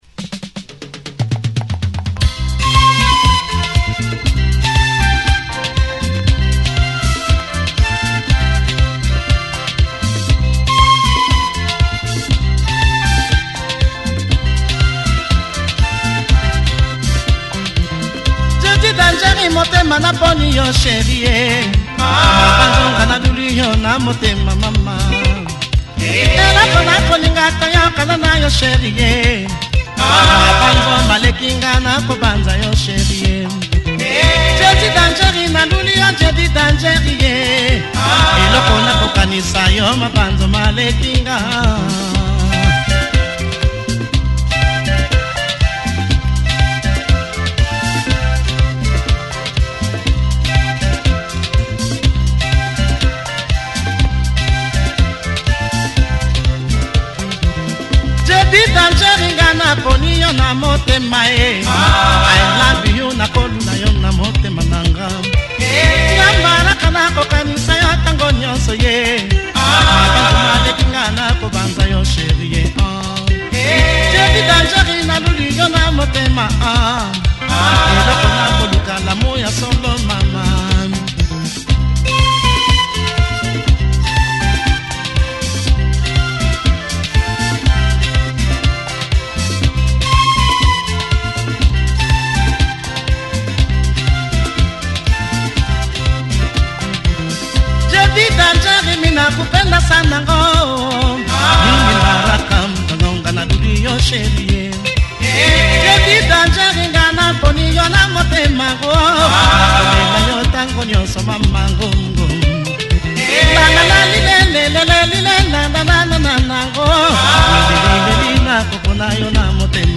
nice catchy, slight Congo vibe
reggae flavored nugget